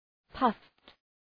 Προφορά
{pʌft}